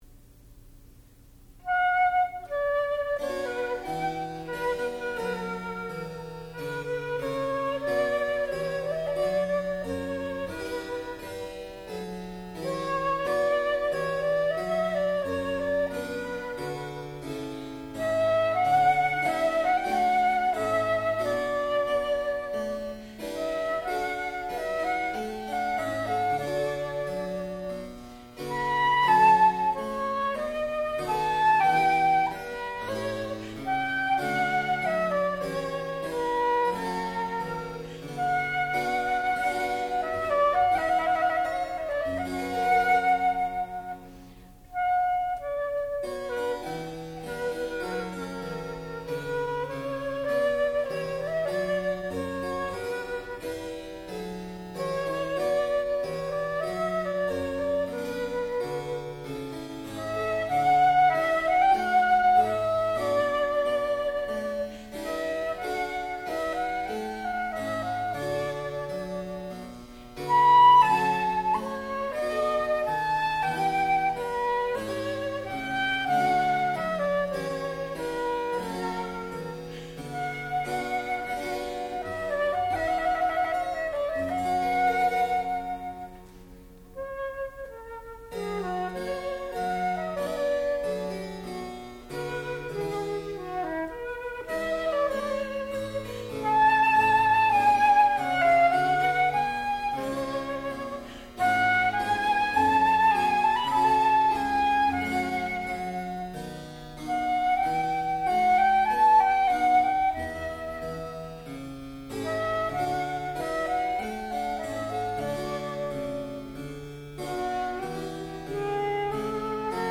sound recording-musical
classical music
harpsichord
flute
Junior Recital